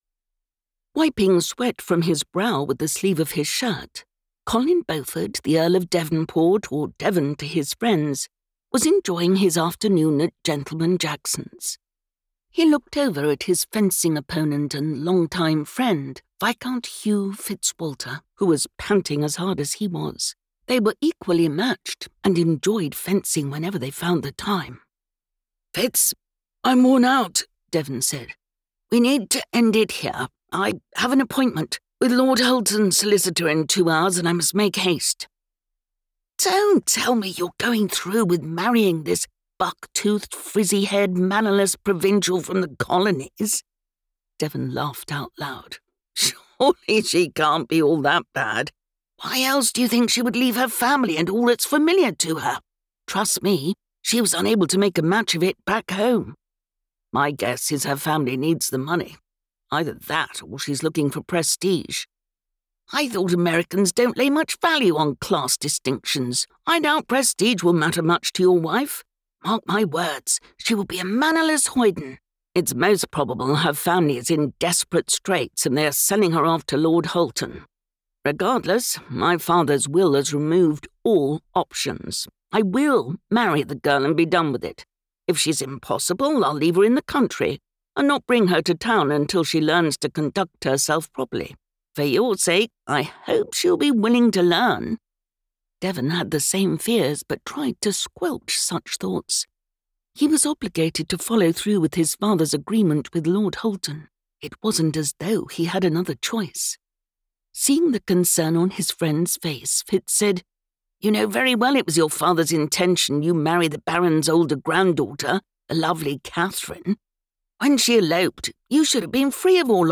10 hrs 57 mins Unabridged Audiobook Release date